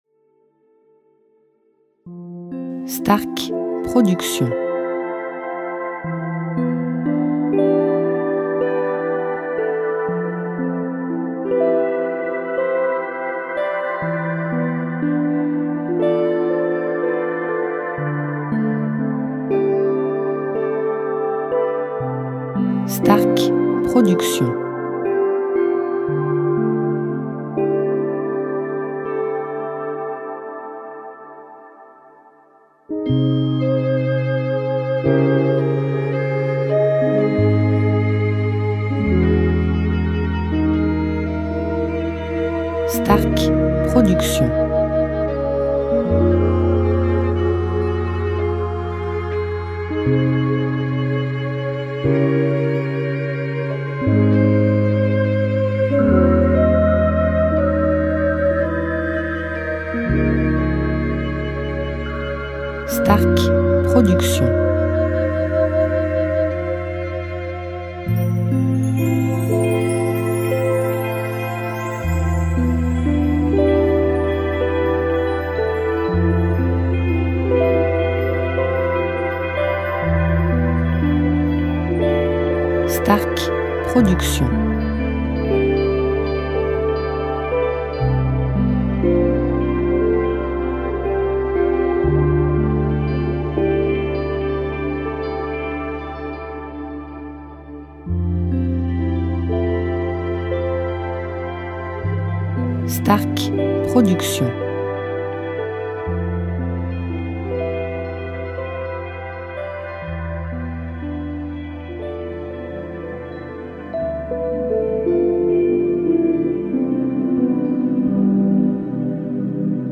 style Relaxation Worldmusic durée 1 heure